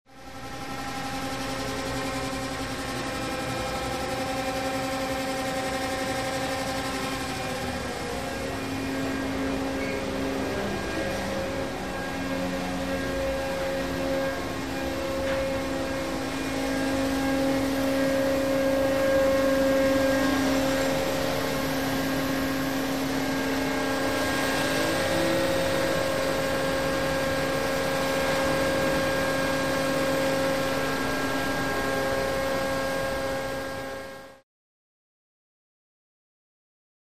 Machine Whir With With Hum And Fan Drone That Gets Closer And Away